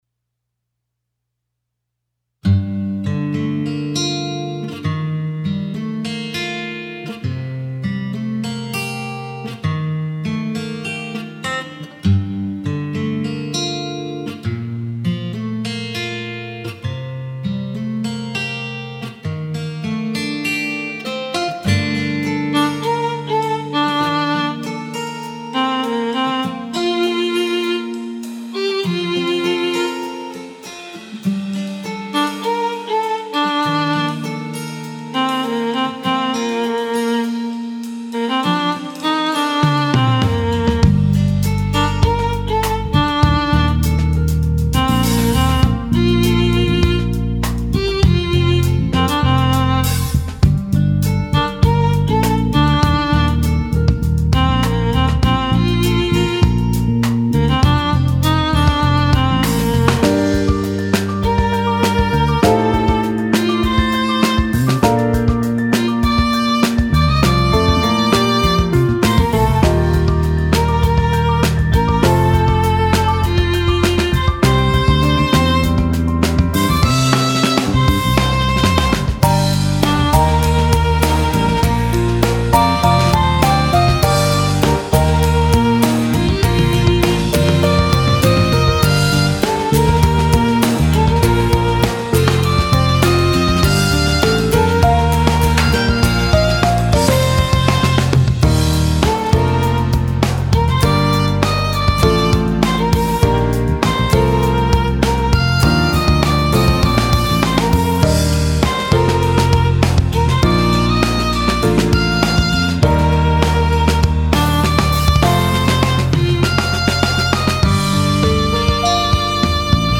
ちなみにボカロの歌はまだ入ってません。
アコースティック・ギターのバラード・ロックの楽曲デモ
こちらも2015年くらいに制作していたバラード風のロック曲です。
やや暗くメロディックな曲調です。
アコースティック・ギターとベースとドラム、ピアノのシンプル構成のデモです。ヴァイオリンで仮メロディーを入れてます。